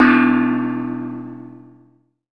Index of /90_sSampleCDs/300 Drum Machines/Akai MPC-500/3. Perc/ChinesePrc